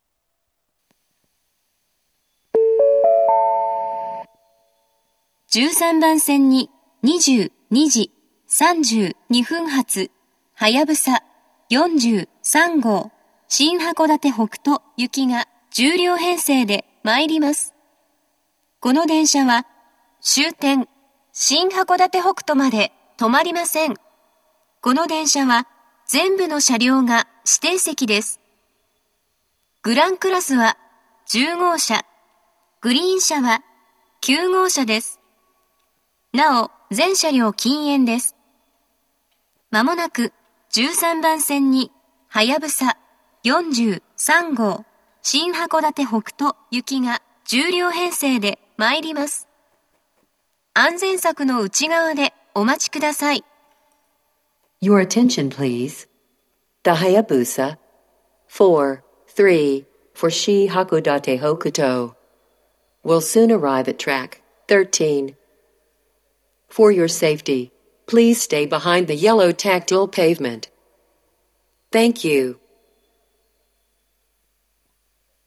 １３番線接近放送
接近放送及び到着放送は「はやぶさ４３号　新函館北斗行」です。